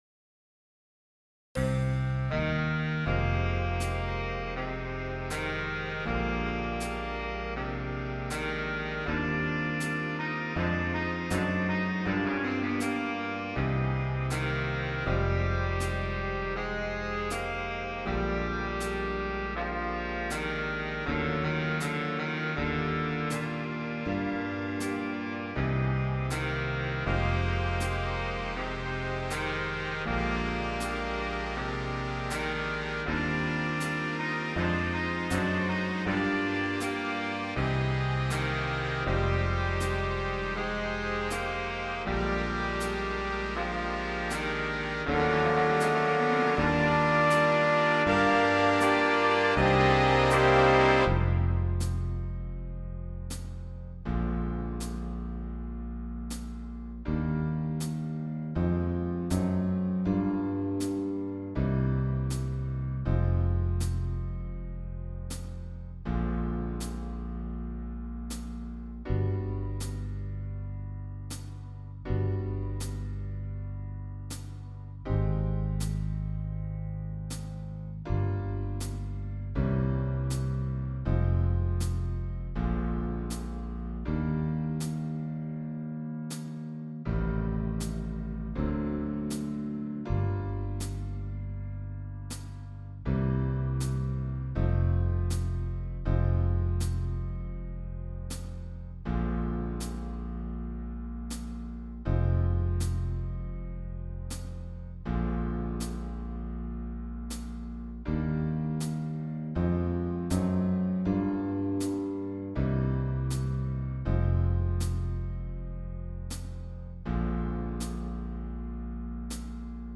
• Beats Per Minute: = 94
• Key Signature: Eb
• Time Signature: 4/4